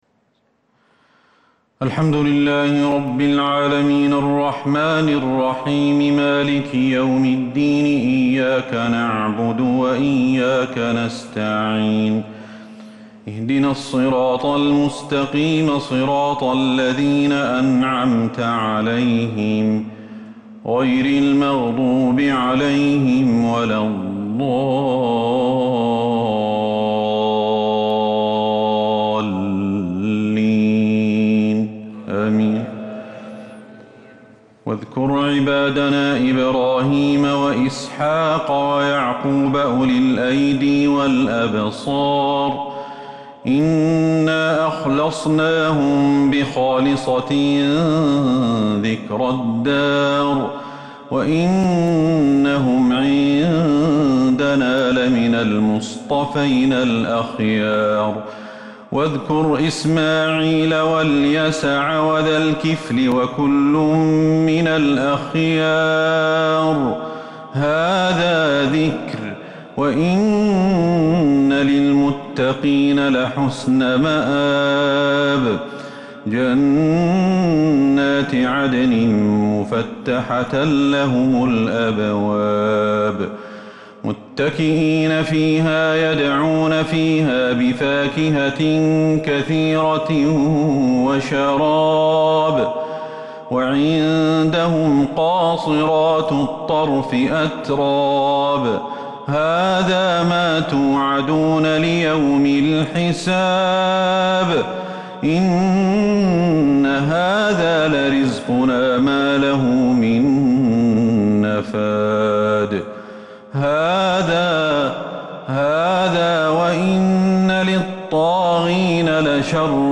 صلاة التهجّد | ليلة 25 رمضان 1442هـ| من سورتي ص(45-88) الزمر (1-52) | Tahajjud prayer | The night of Ramadan 25 1442 | surah Saad and AzZumar > تراويح الحرم النبوي عام 1442 🕌 > التراويح - تلاوات الحرمين